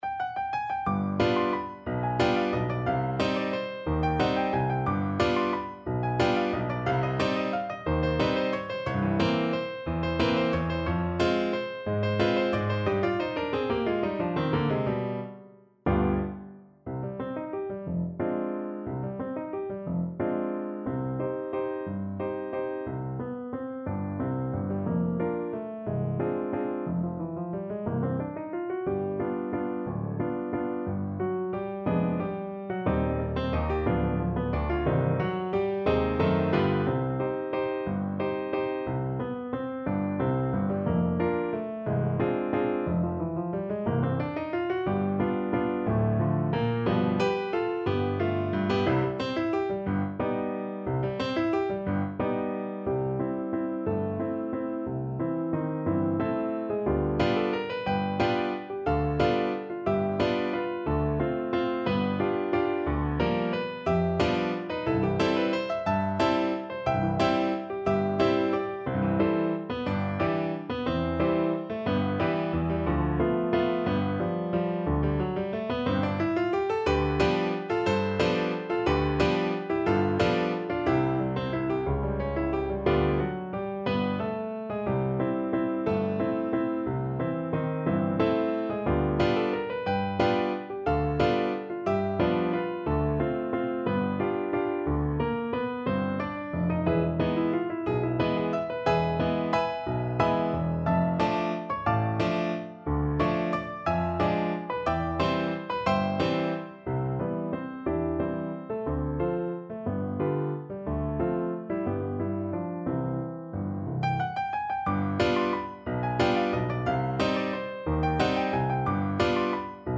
Allegro movido =180 (View more music marked Allegro)
3/4 (View more 3/4 Music)